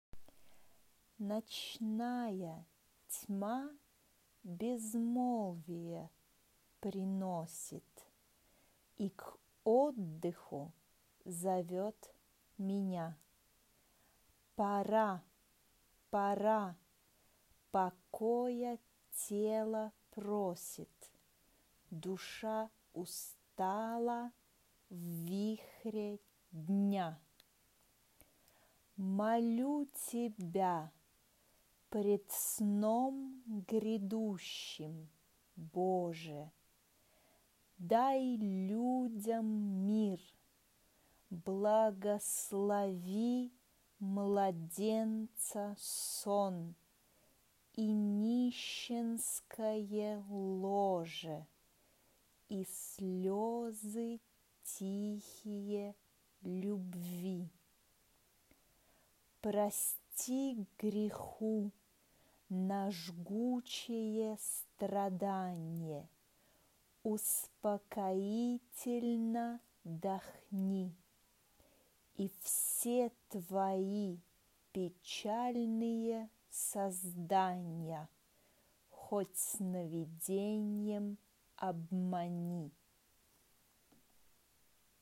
Genre-Style-Forme : Profane ; Chœur Caractère de la pièce : calme
SSATTBB (7 voix mixtes )
Tonalité : do mineur